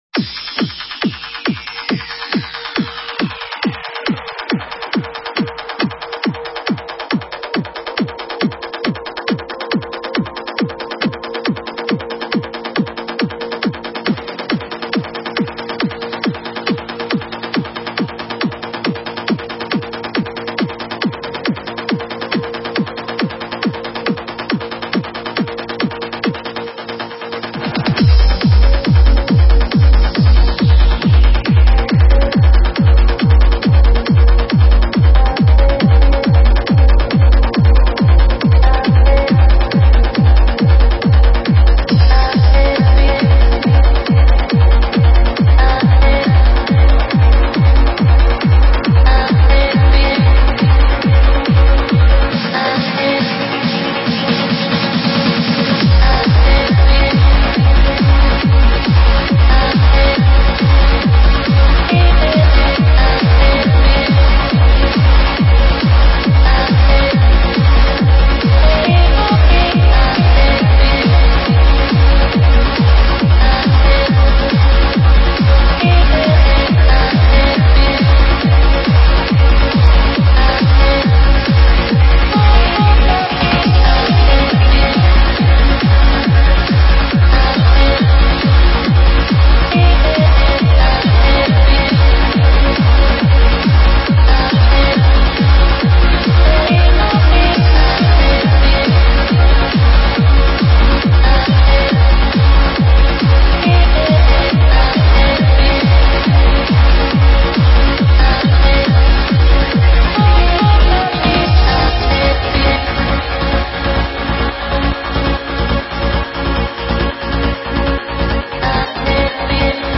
Стиль: Vocal Trance / Uplifting Trance